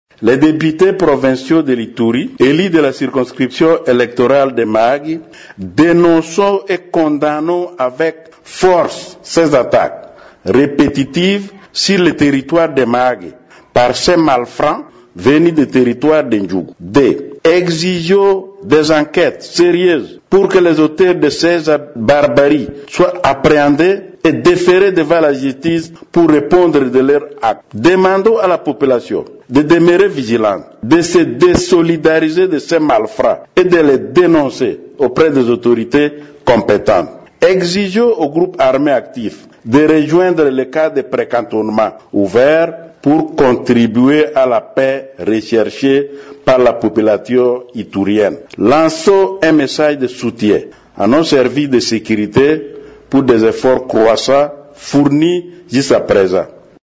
Ils l’ont déclaré mardi 21 janvier au cours d’un point de presse tenu à Bunia.
Vous pouvez suivre l'extrait de leur déclaration, lue par le député Martin Olowa Ryeko.